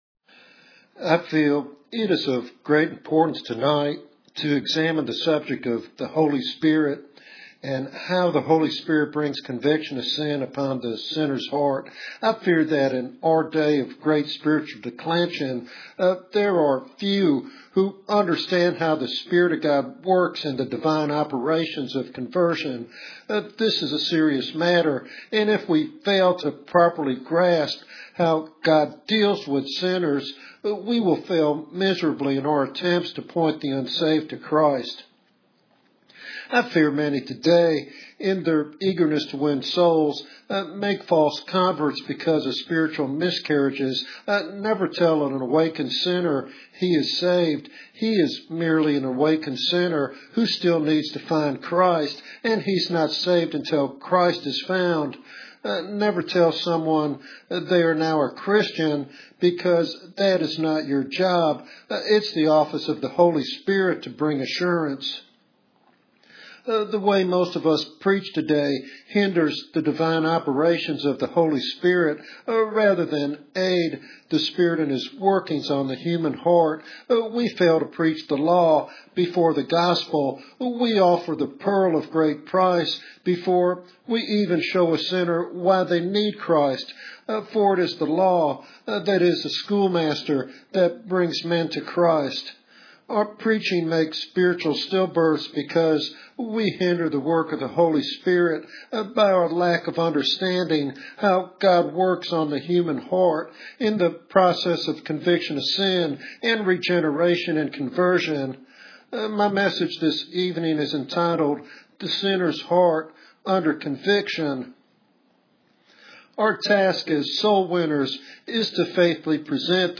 This sermon challenges listeners to understand conviction as the essential first step toward genuine salvation.